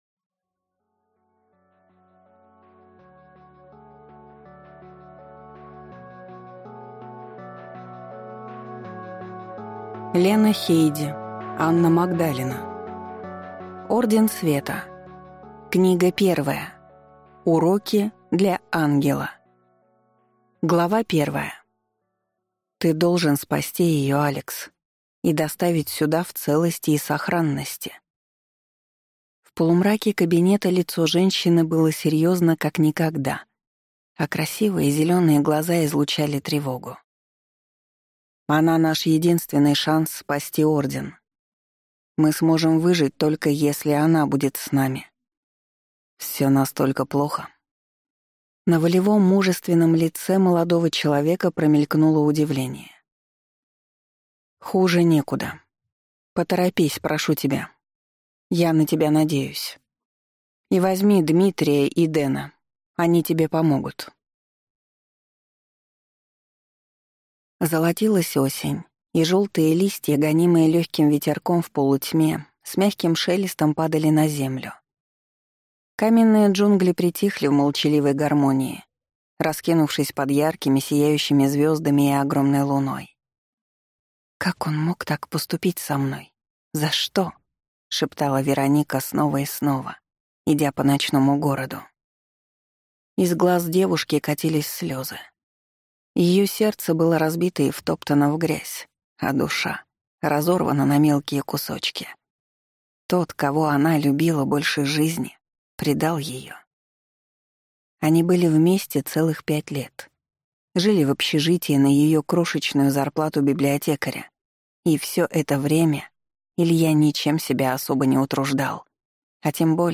Аудиокнига Орден Света. Уроки для ангела | Библиотека аудиокниг